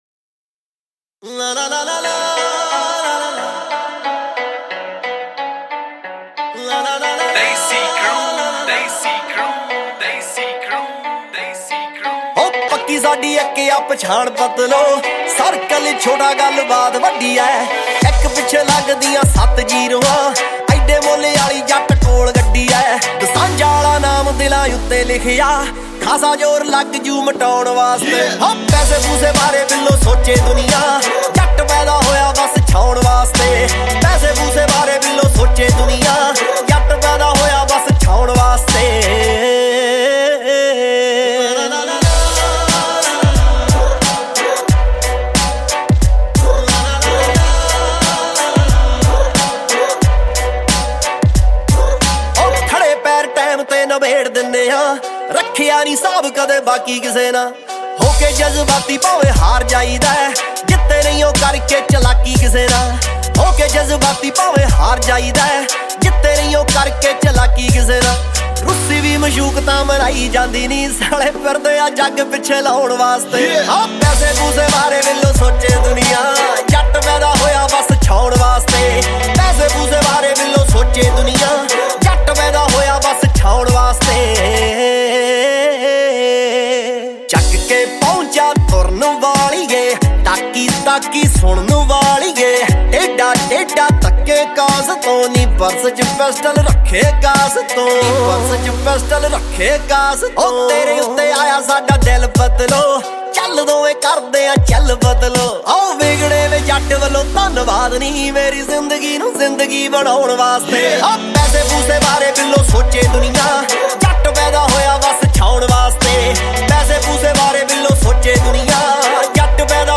2020 Punjabi Mp3 Songs
Punjabi Bhangra MP3 Songs